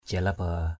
/ʥa-la-ba:/ (d.) rắn hổ mang.